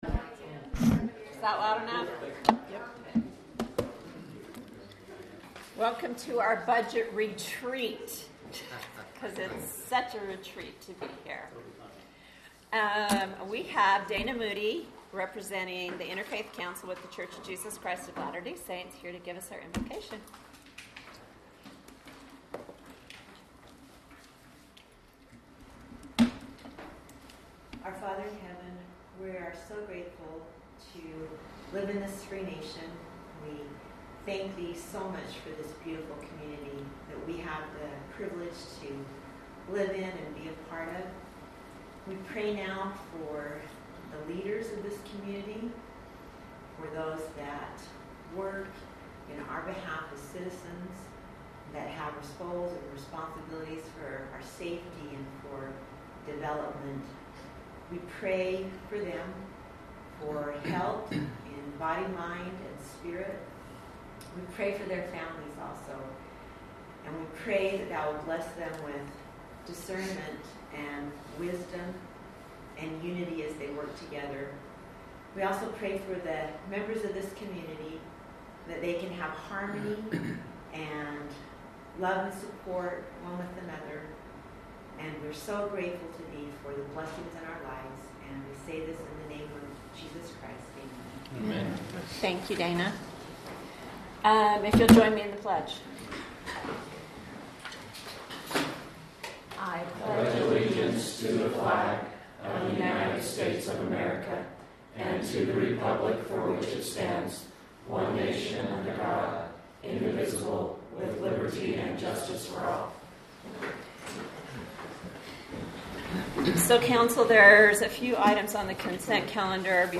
City Council Meeting Agenda